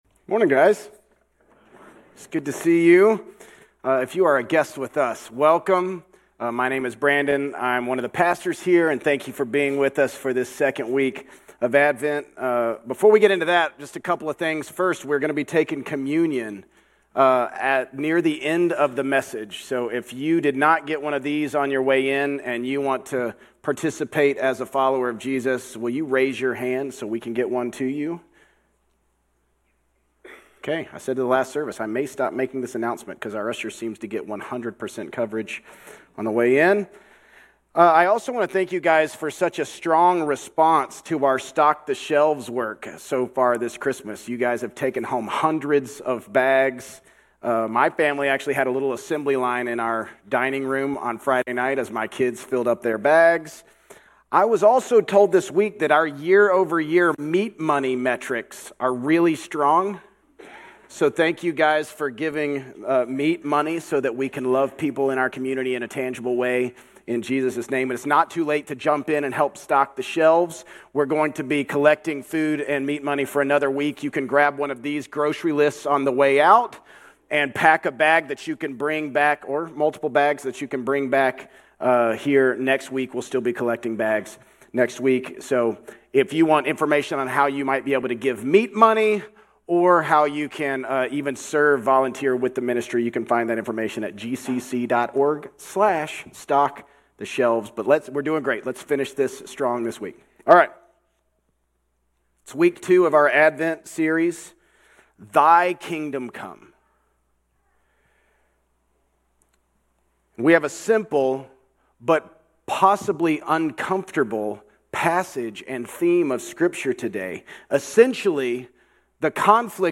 Grace Community Church Old Jacksonville Campus Sermons 12_7 Old Jacksonville Campus Dec 08 2025 | 00:33:48 1x 00:00 / 00:33:48 Subscribe Share RSS Feed Share Link Embed